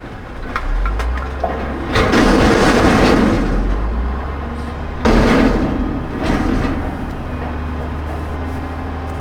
vehicle
dump.ogg